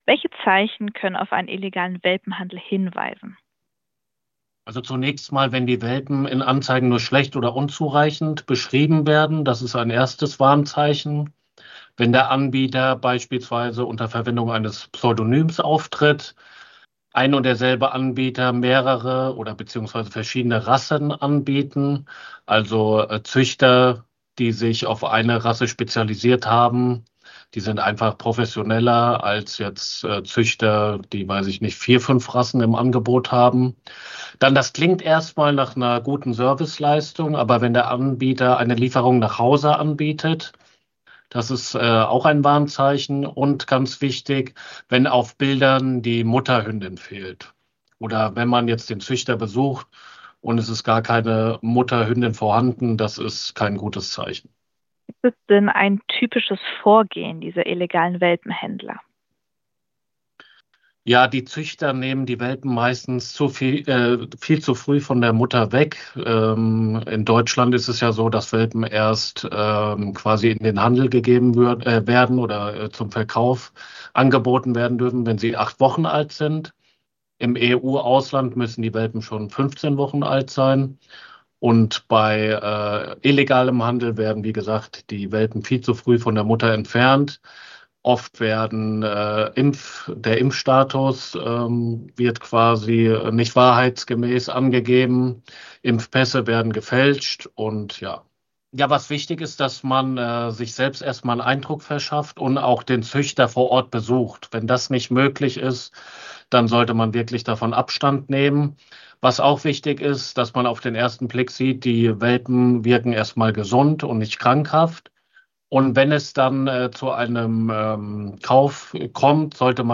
Tipps vom LKA - im Interview